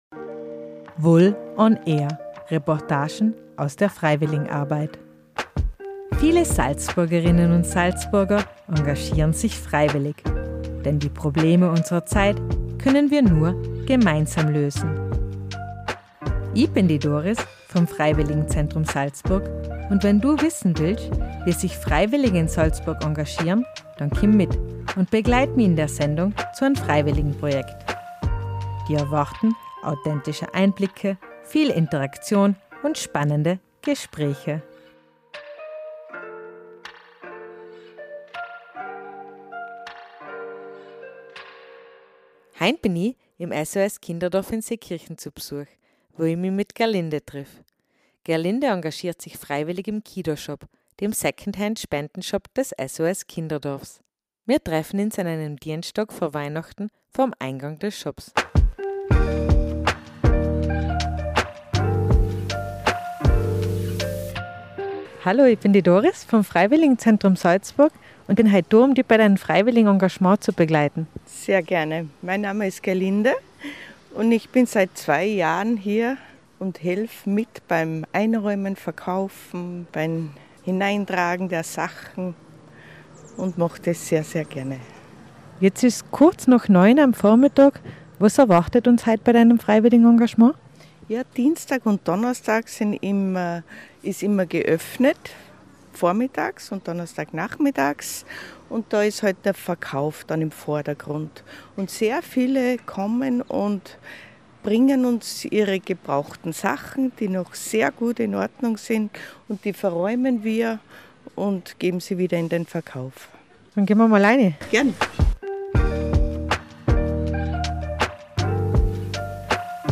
VOL ON AIR –- Reportagen aus der Freiwilligenarbeit